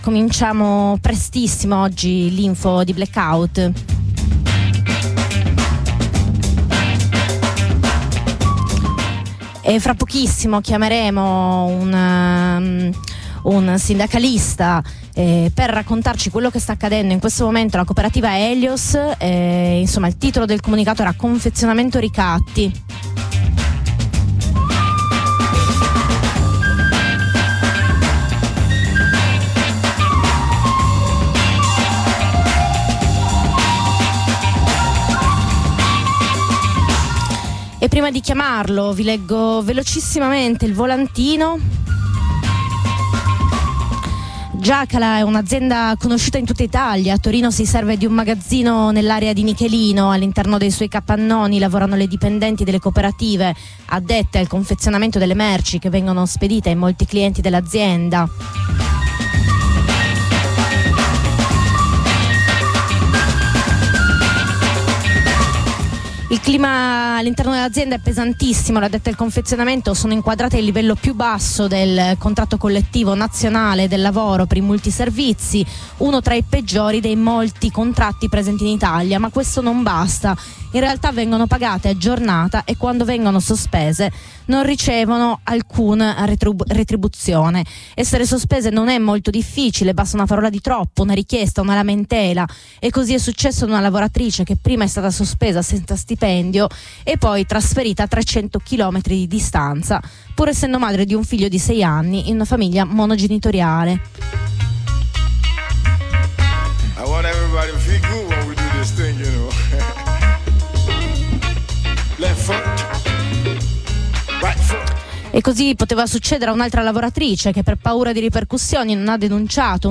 Mattinata informativa iniziata in anticipo per seguire in diretta il picchetto davanti ai cancelli dello stabilimento Jakala di Nichelino dove operano le dipendenti della cooperativa Helios, organizzato da Non Una di Meno con Flaica Cub.
La prima diretta alle ore 7:30: